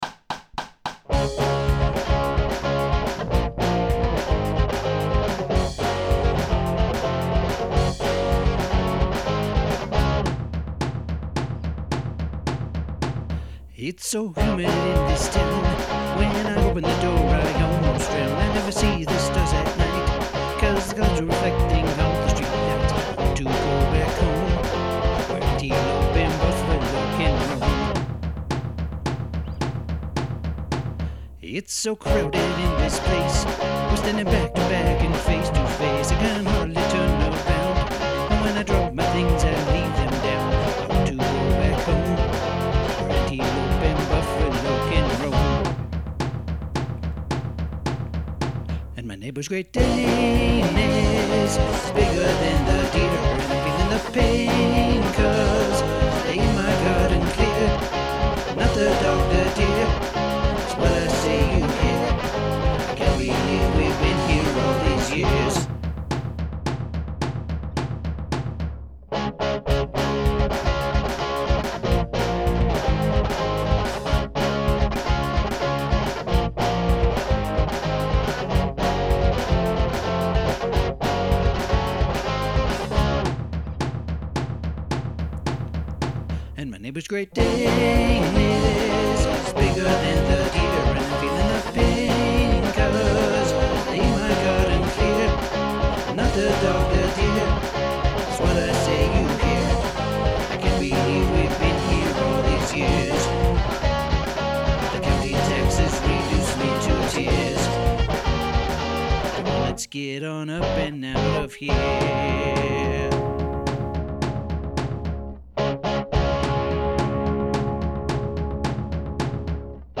allTheseYears_rhythmDoublingBetterIHope.mp3